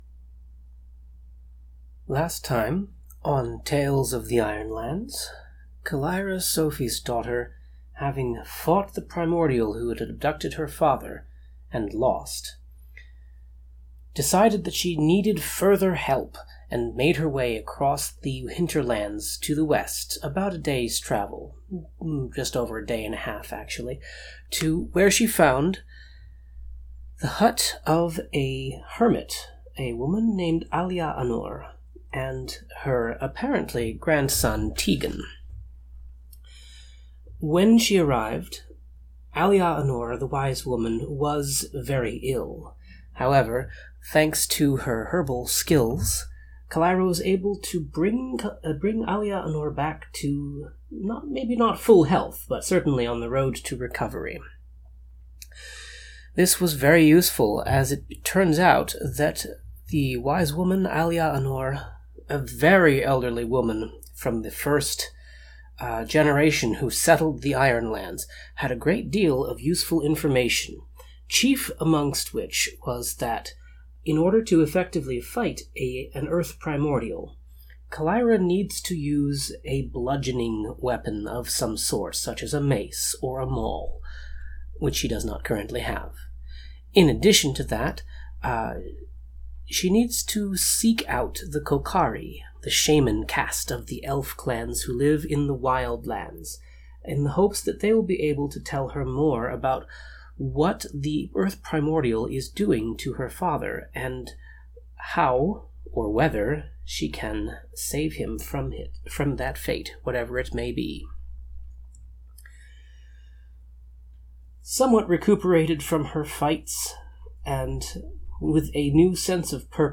Note: This has been edited to remove uninteresting bits such as silence, throat-clearing and paper shuffling.